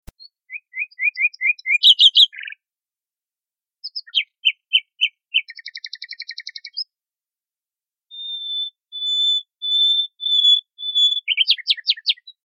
Rossignol philomèle
Luscinia megarhynchos
Le rossignol philomèle est le plus souvent très discret et donc difficile à observer mais son chant beau sonore trahit sa présence.
rossignol.mp3